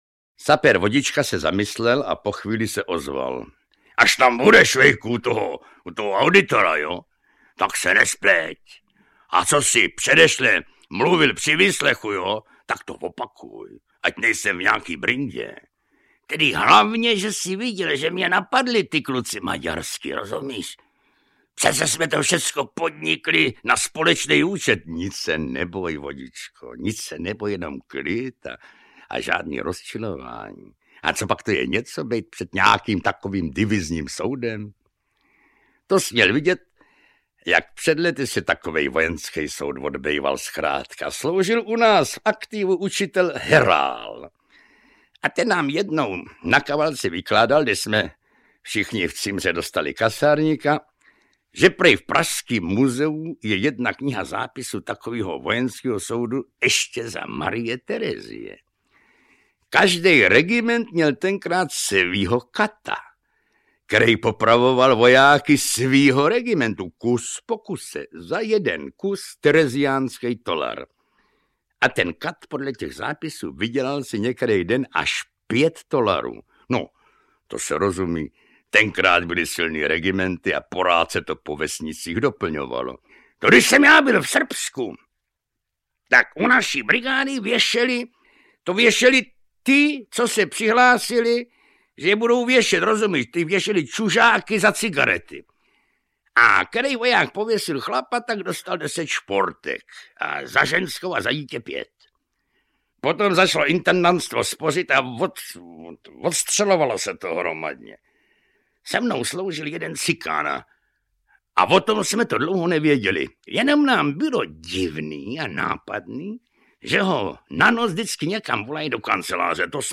Pokračujeme ve vydávání Haškových Osudů dobrého vojáka Švejka, čtených Janem Werichem.
Audiokniha
Čte: Jan Werich